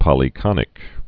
(pŏlē-kŏnĭk)